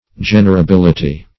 Generability \Gen`er*a*bil"i*ty\, n.
generability.mp3